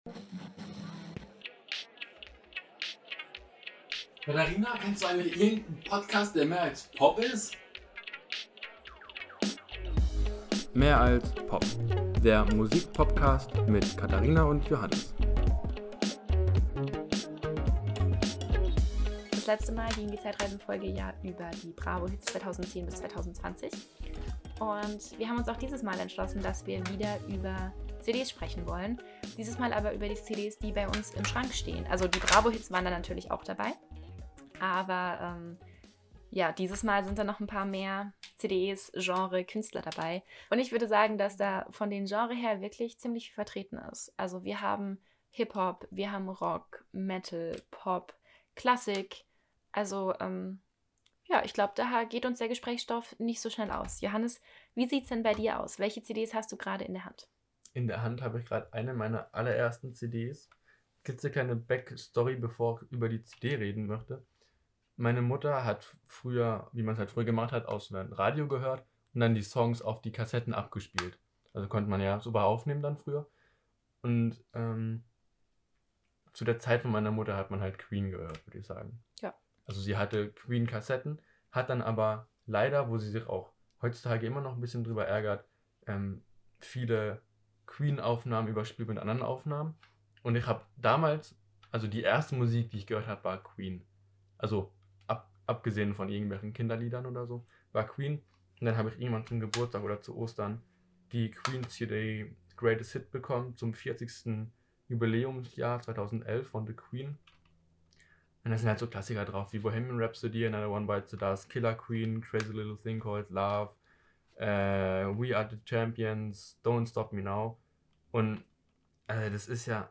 Das bedeutet, dass wir wieder einfach drauf los gequatscht haben und zwar über die CDs, die bei uns im Regal stehen.
Aber in der Folge ist trotzdem ganz viel gute Musik dabei.